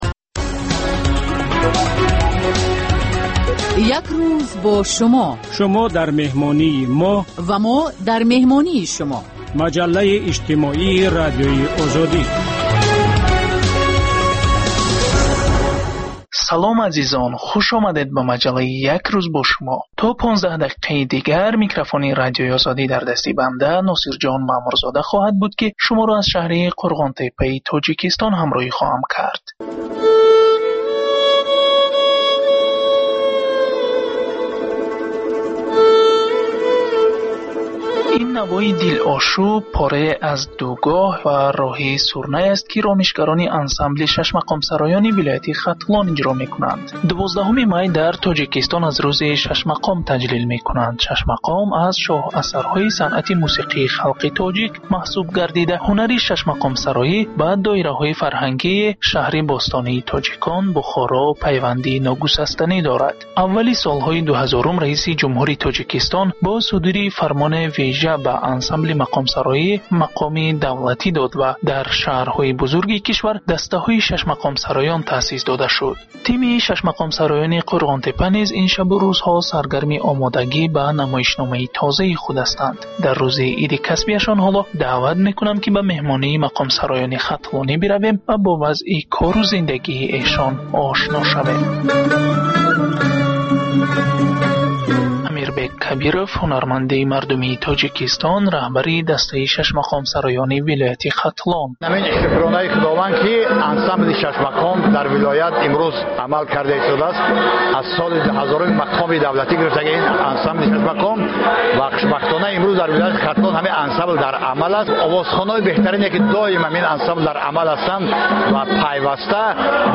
Новости стран Центральной Азии.